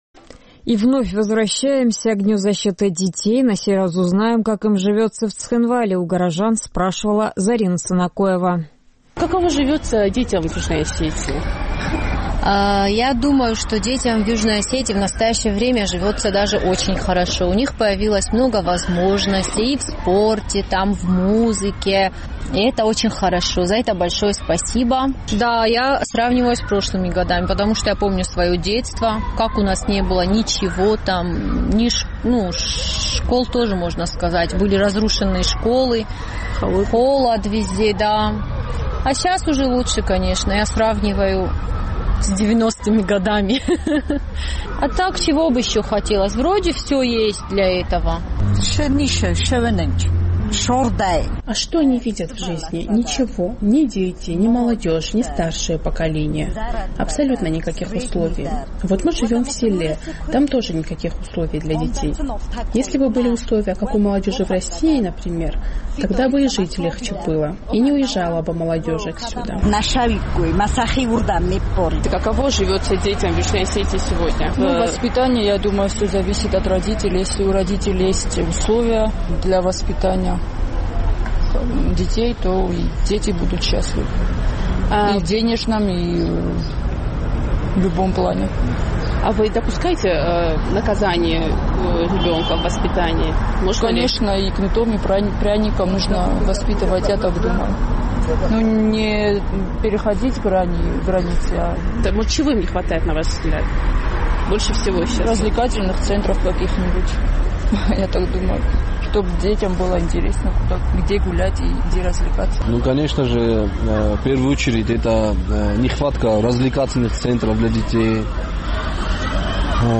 Наш цхинвальский корреспондент поинтересовалась у жителей югоосетинской столицы, как живется сегодня детям в республике.